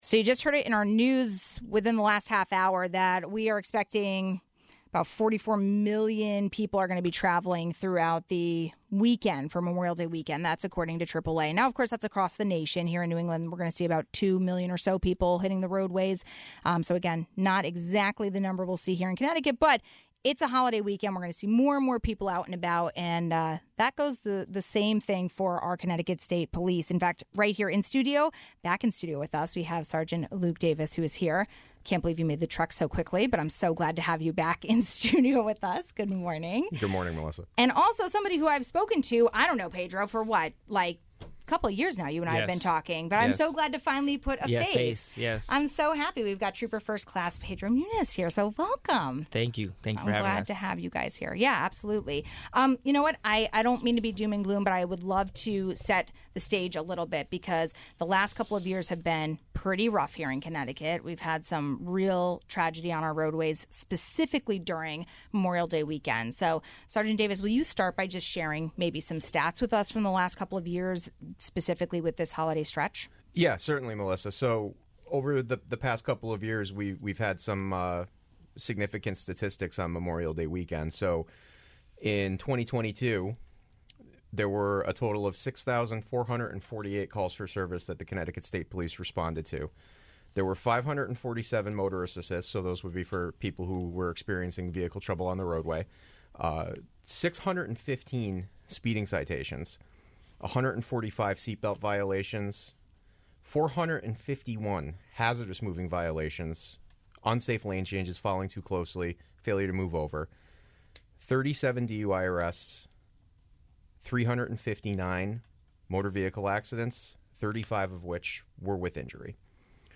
came in studio to share updates on patrols and messages to all drivers.